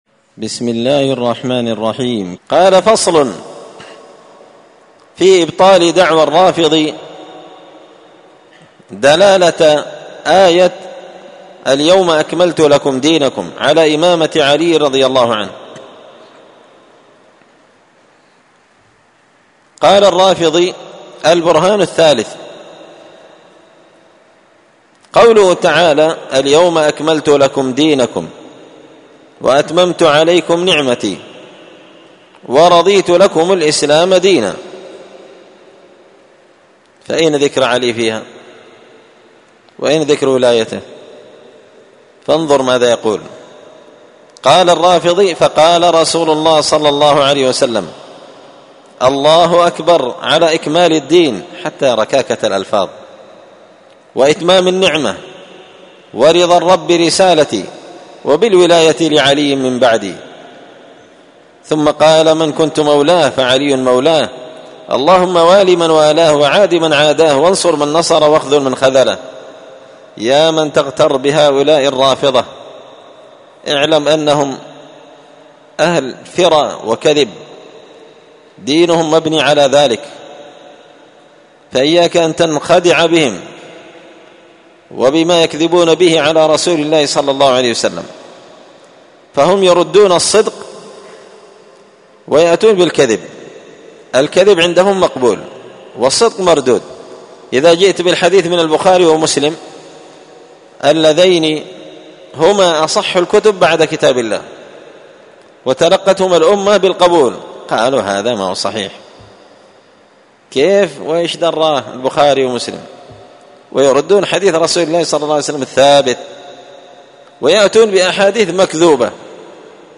الأربعاء 22 محرم 1445 هــــ | الدروس، دروس الردود، مختصر منهاج السنة النبوية لشيخ الإسلام ابن تيمية | شارك بتعليقك | 84 المشاهدات
مسجد الفرقان قشن_المهرة_اليمن